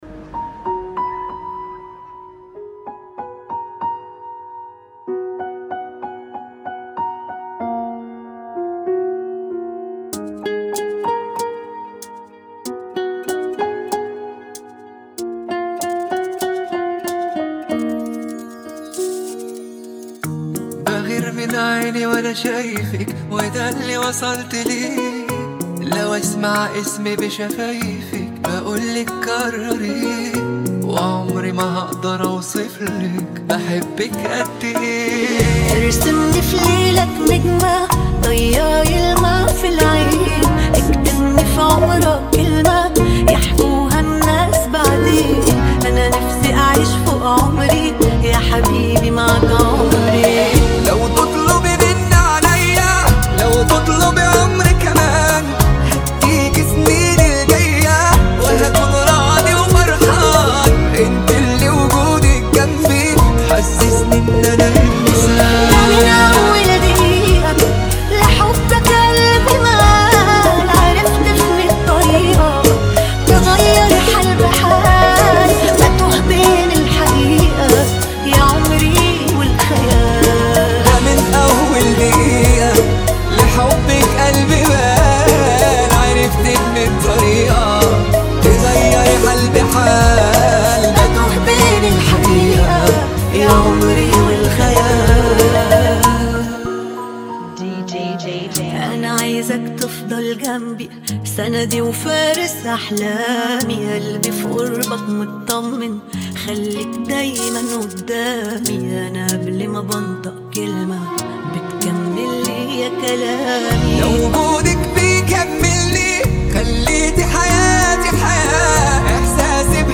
94 BPM
Genre: Bachata Remix